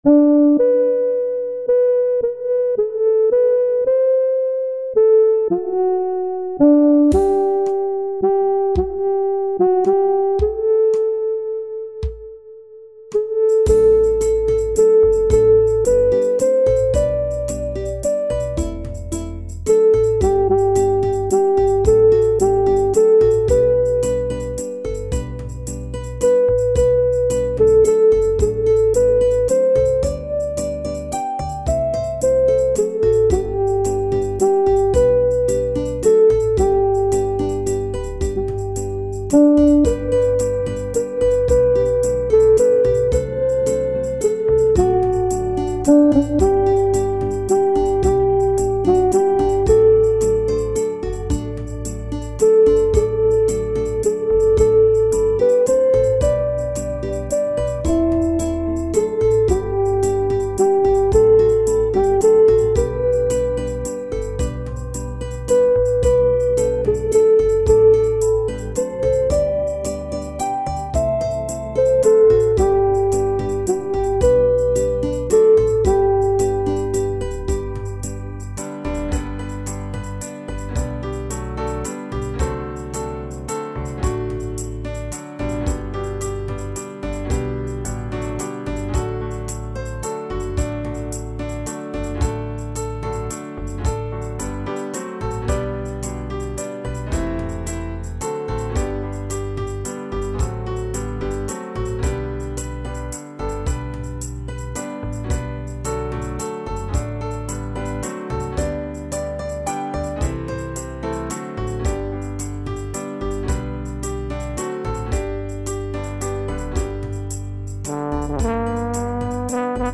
German "Volkslied"
Ancient German Folk